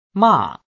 The 4th tone “ˋ” like in mà Falling (